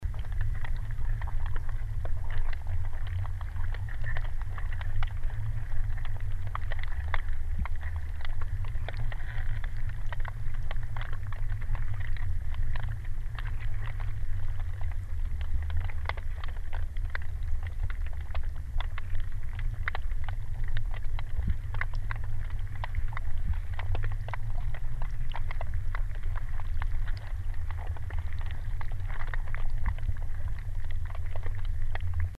Образец записи с гидрофона Соната
Запись сделана с помощью портативного рекордера Zoom H5.